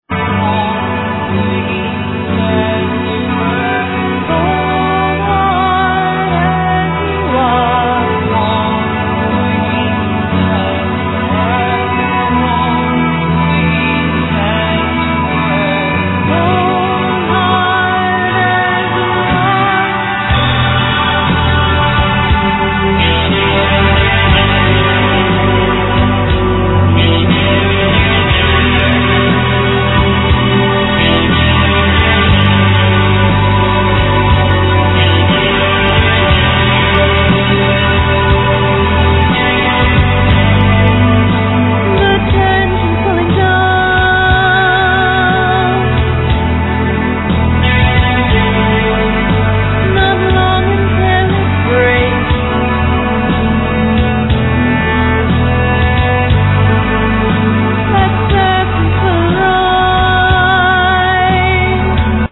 Vocals
Violin
Guitars, Bass, Programming
Drums